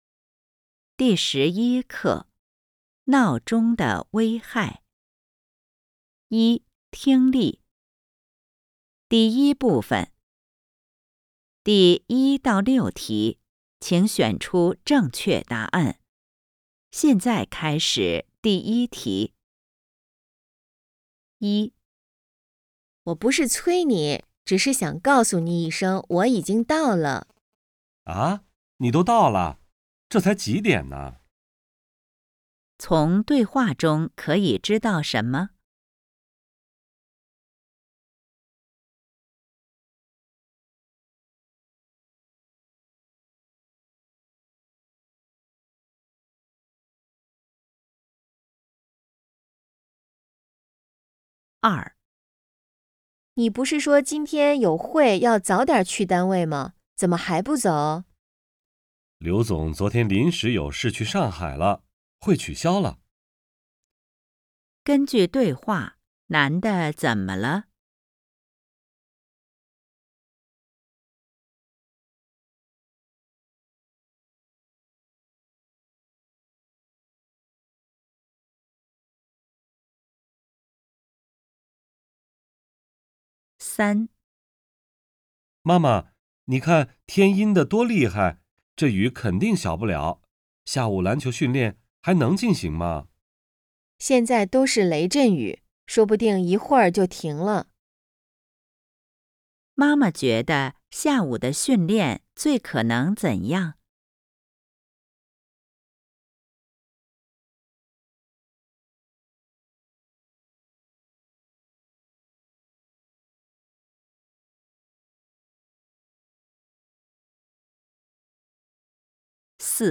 一、听力
• Hỏi: Từ đoạn hội thoại có thể biết được điều gì?